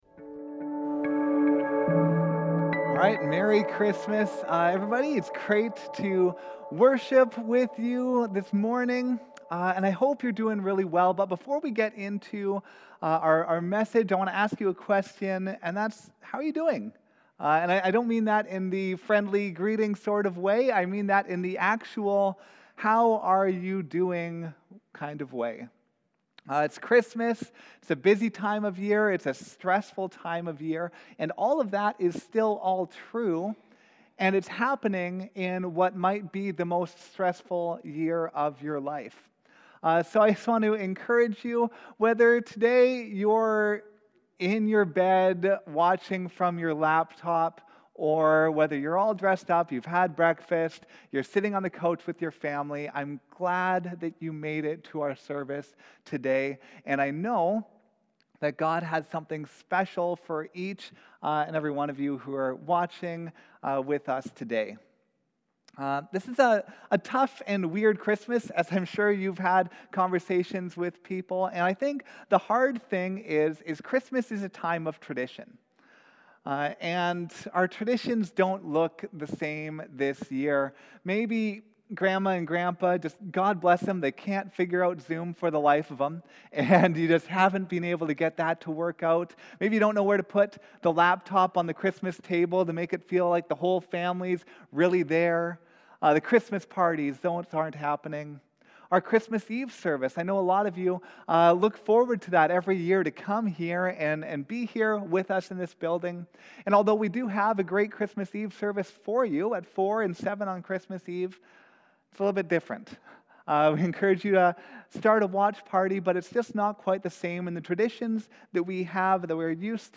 Sermons | Saanich Baptist Church